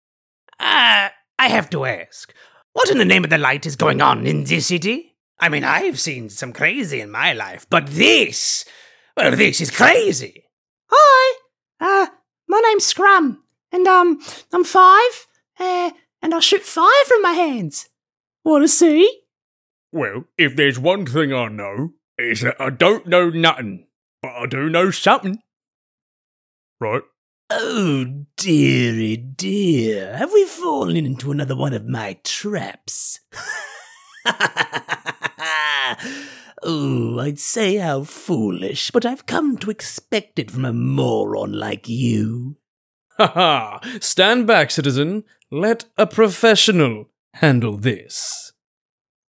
Male
Teenager (13-17), Yng Adult (18-29)
I can use my voice for a chipper medium to high pitched character full of energy, to a rich an intimate style more suited for narration.
Character / Cartoon
Some Of My Kooky Characters
All our voice actors have professional broadcast quality recording studios.